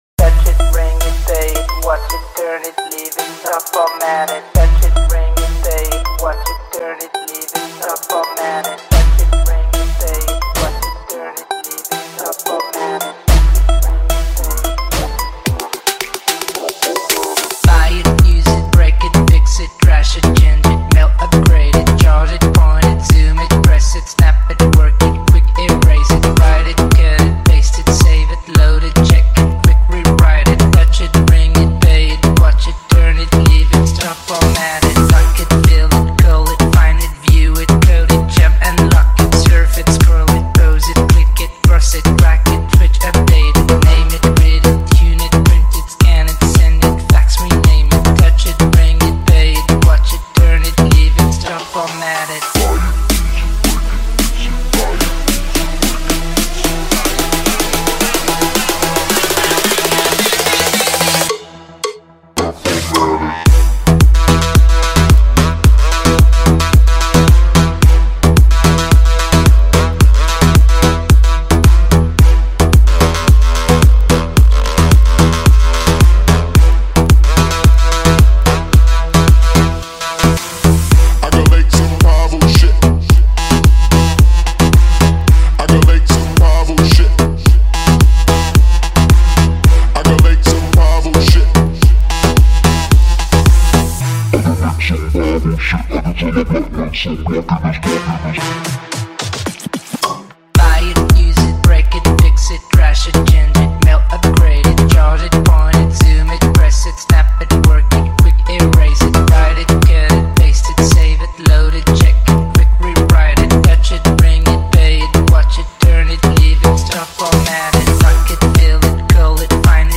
Balkan Slavic Bass Boosted 2025
Hard bass, ethnic vibes, and pure adrenaline